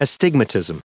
387_astigmatism.ogg